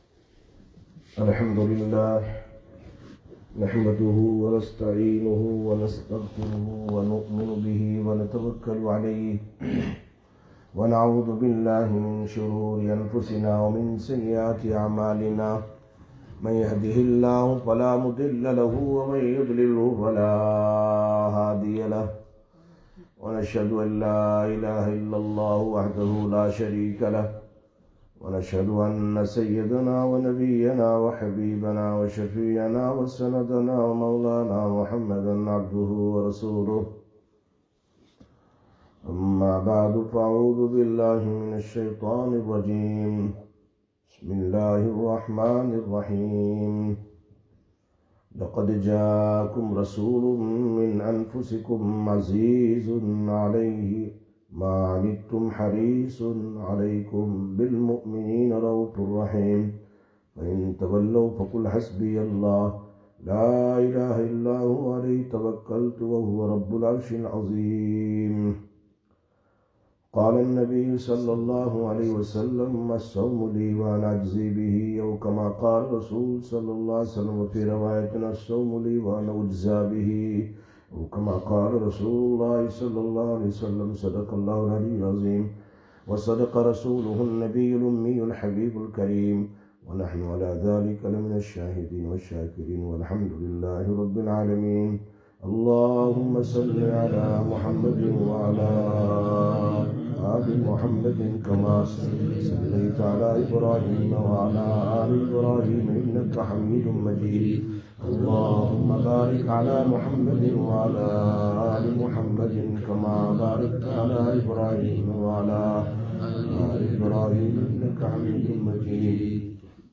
25/04/2021 Madrasah Rashidiya Bayaan